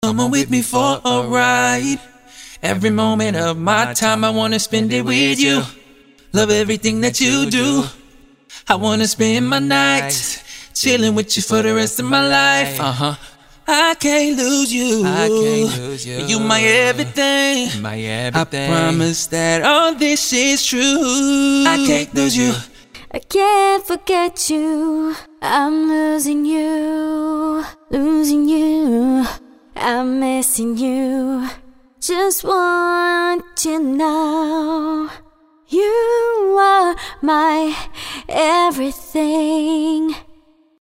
This premium loop pack is a treasure trove of rich, emotive vocal performances, designed to bring your productions to life.
92 Female Vocal Loops: Explore a diverse array of female vocal loops that span various styles and emotions. From angelic melodies to powerful hooks, these loops are perfect for adding a touch of grace and beauty to your tracks.
Whether you need a smooth croon, a gritty belt, or anything in between, these loops provide the perfect complement to your productions.
Layered and harmonized to perfection, these stacks will add richness and complexity to your compositions.